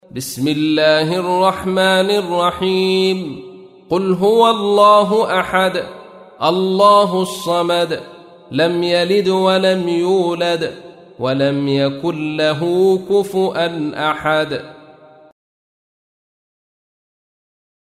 تحميل : 112. سورة الإخلاص / القارئ عبد الرشيد صوفي / القرآن الكريم / موقع يا حسين